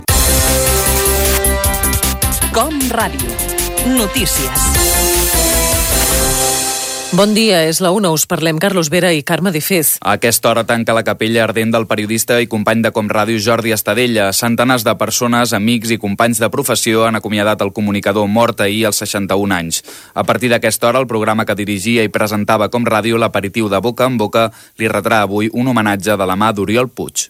Butlletí horari. Capella ardent del presentador Jordi Estadella.
Informatiu